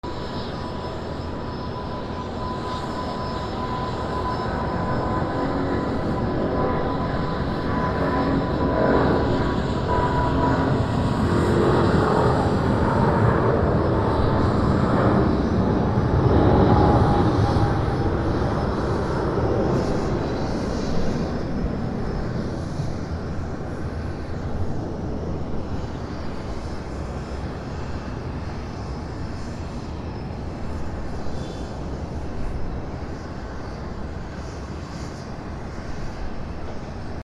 飛行機 離陸
/ E｜乗り物 / E-80 ｜飛行機・空港
416 NT4 mix